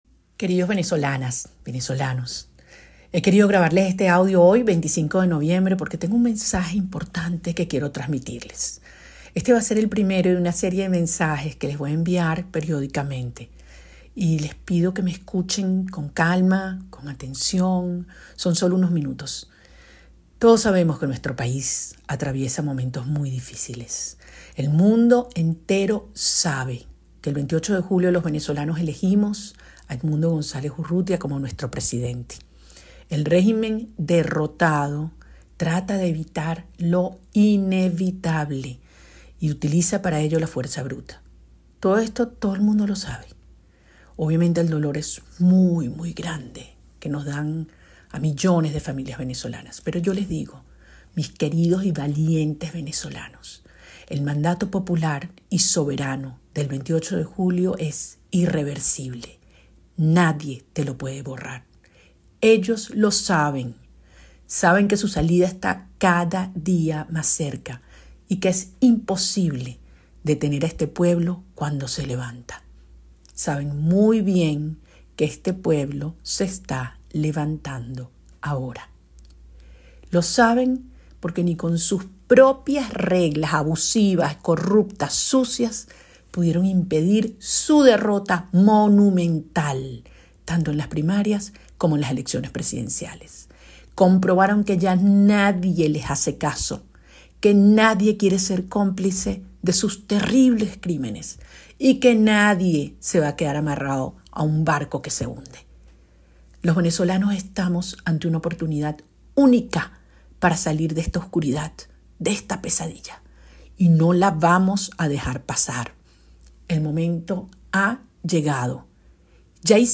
La líder opositora, María Corina Machado, envió este lunes su primer mensaje a los venezolanos de cara al 10 de